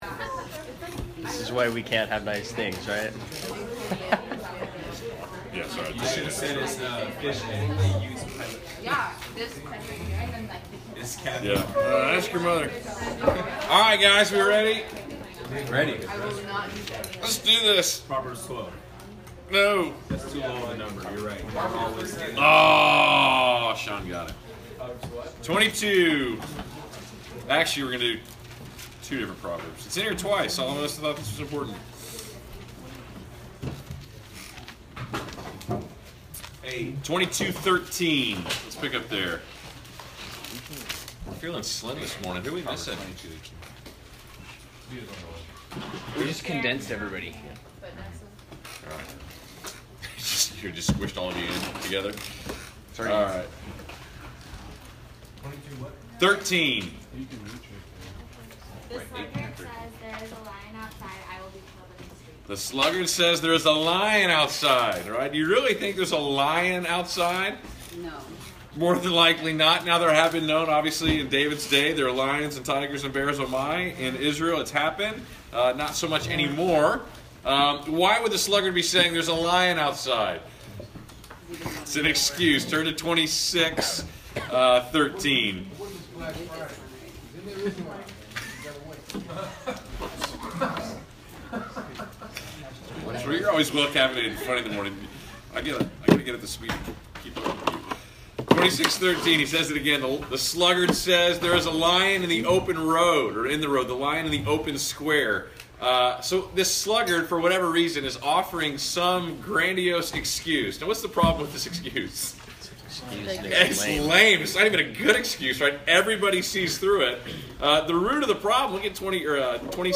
Class Session Audio November 17